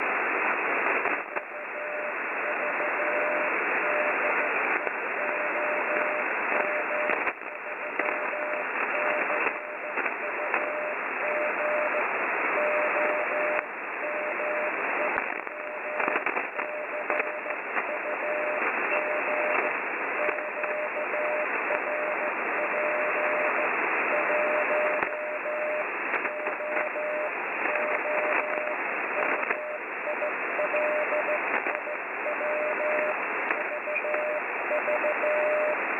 takto počul 449 svoje značky na WebSDR RXe S5 v lokátore JN75NT čo je vzdialenosť 465km.